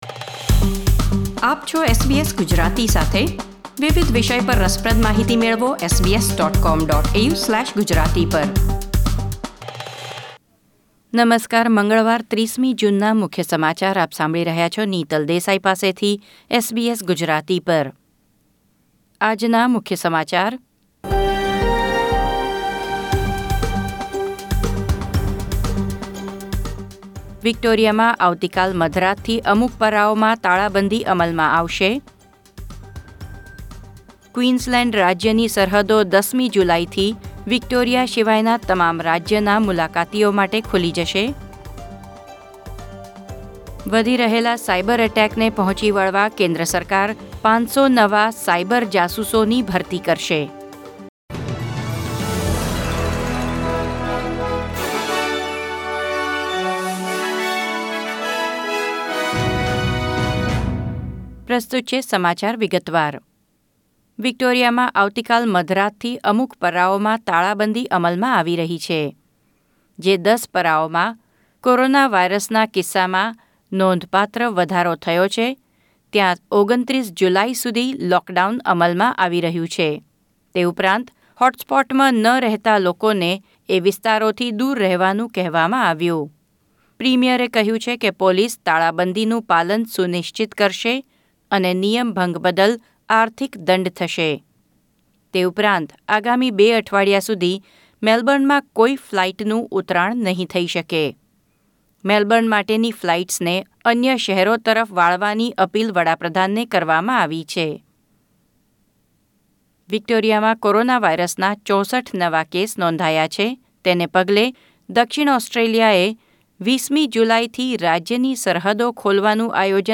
SBS Gujarati News Bulletin 30 June 2020